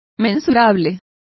Complete with pronunciation of the translation of measurable.